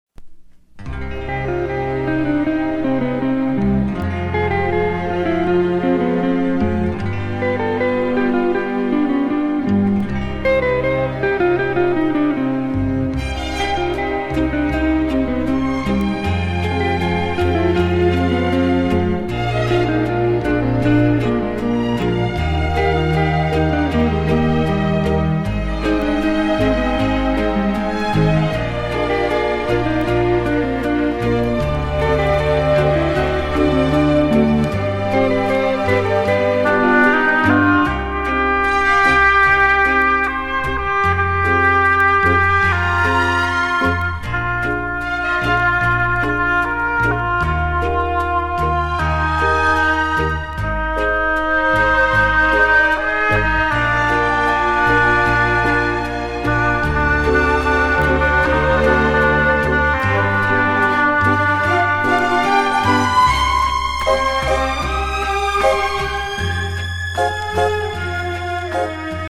Style:Easy Listening